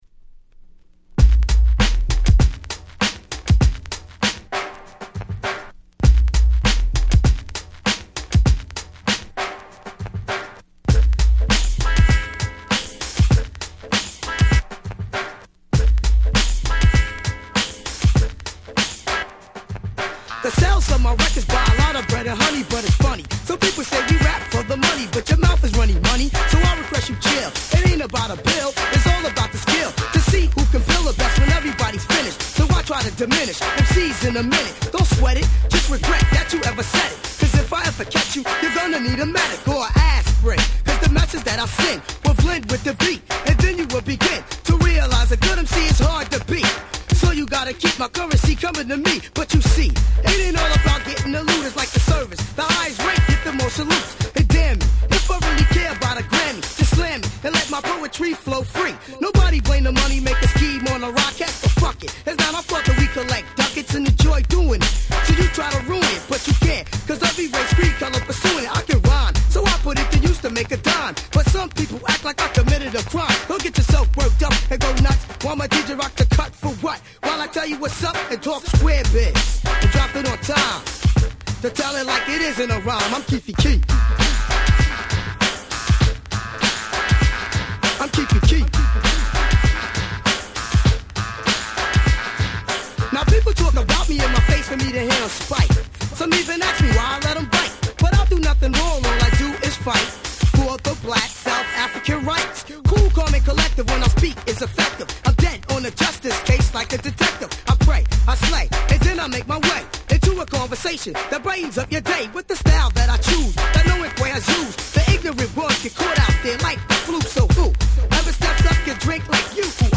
93' Dope Hip Hop !!